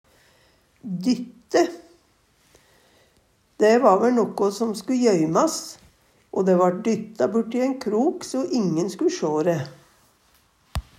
dytte - Numedalsmål (en-US)